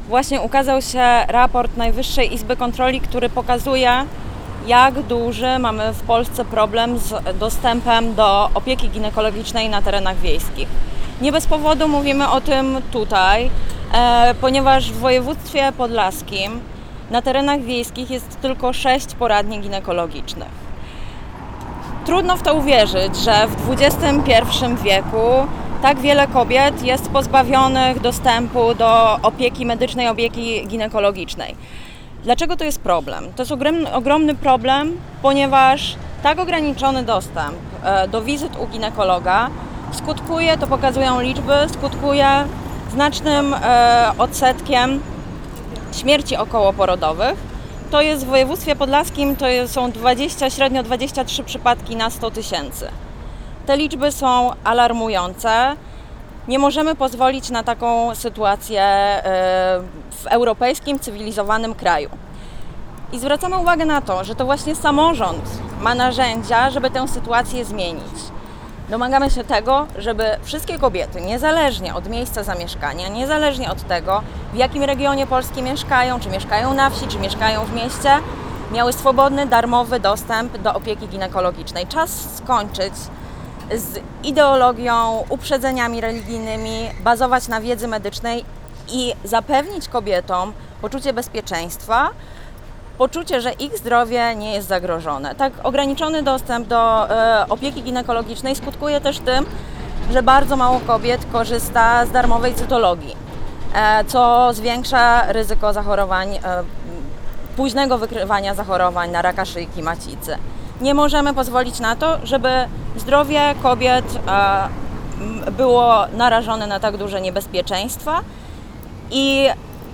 Brefing prasowy przed Urzędem Miejskim w Suwałkach był okazją do poruszenia kilku kwestii, znajdujących się w kręgu zainteresowania członków Razem.